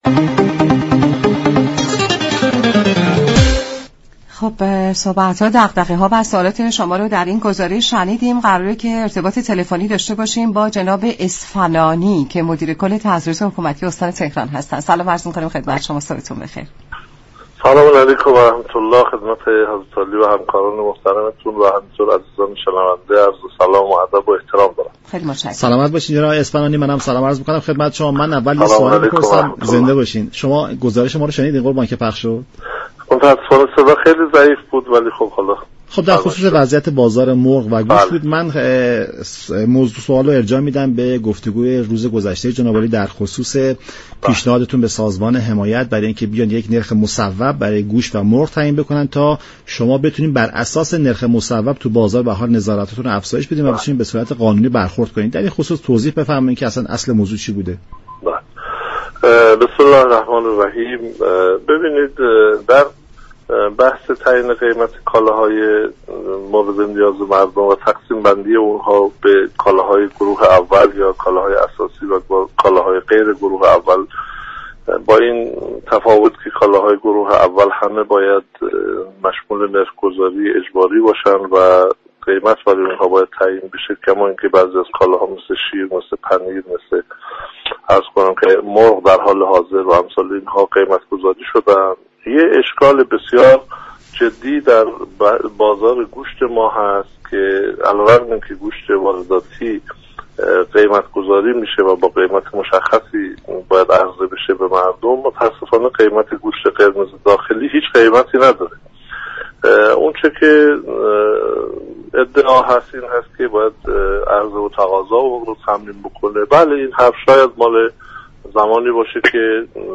مدیر كل سازمان تعزیرات حكومتی استان تهران در گفت و گو با رادیو ایران گفت: اگر نرخ ها تثبیت شده نباشد، سازمان تعزیرات حكومتی هیچ گونه اقدامی را نمی تواند انجام دهد.